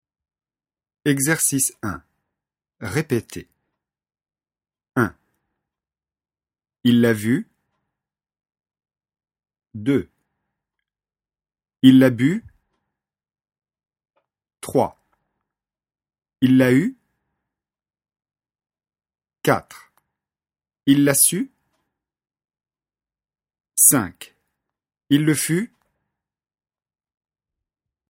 Leçon de phonétique, niveau débutant (A1).
Exercice 1 : répétez.